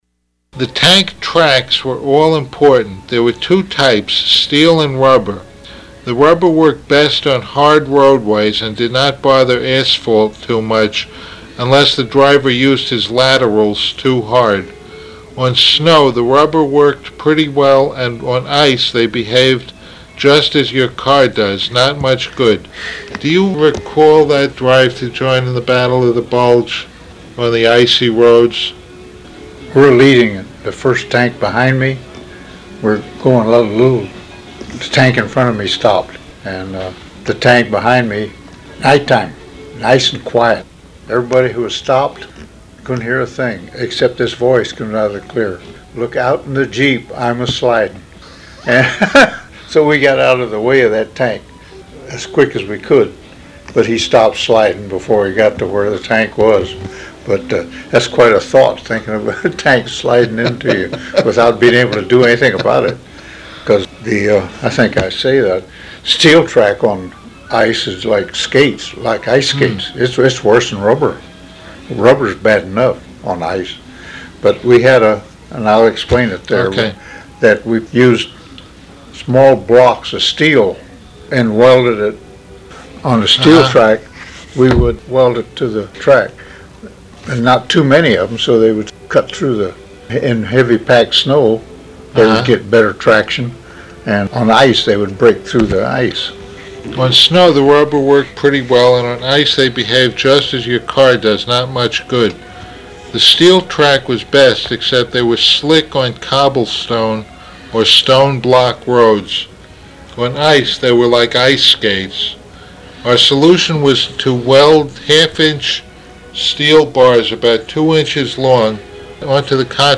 Also, he showed me photographs and described them.
The interview spanned two days, and filled five 90-minute audiocassettes and about 20 minutes of a sixth.